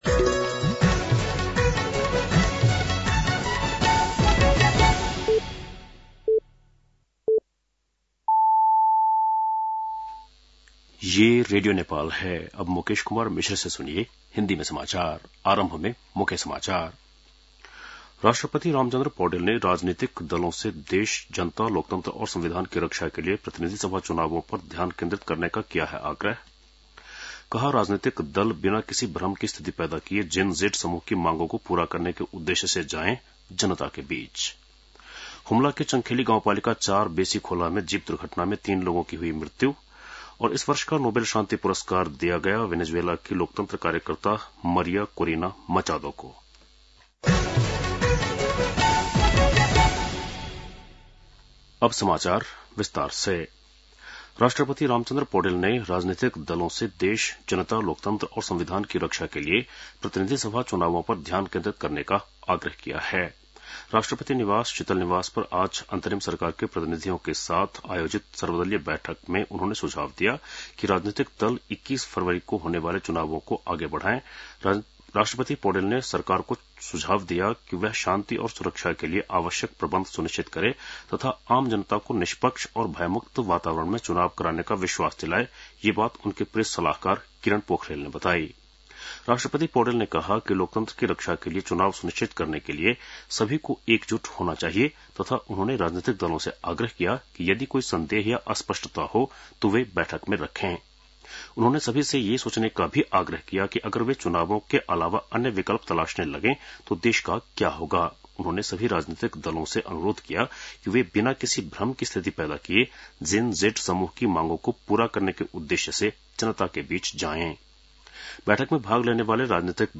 An online outlet of Nepal's national radio broadcaster
बेलुकी १० बजेको हिन्दी समाचार : २४ असोज , २०८२